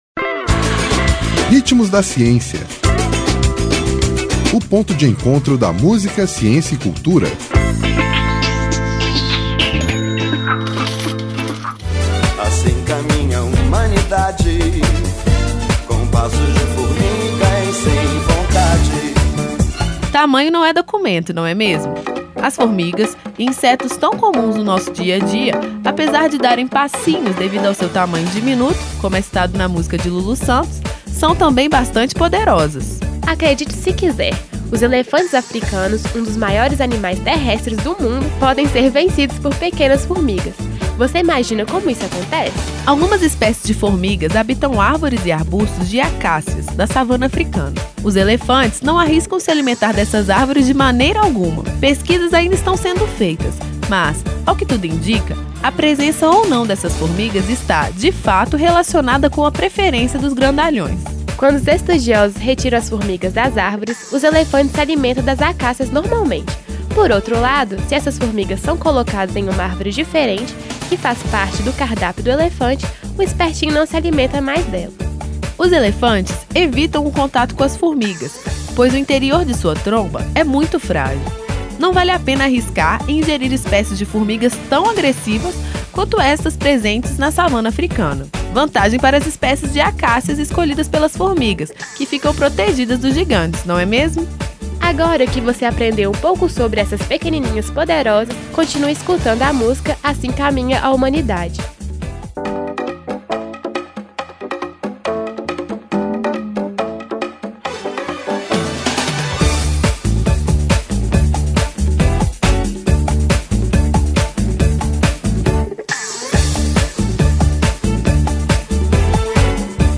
Intérprete: Lulu Santos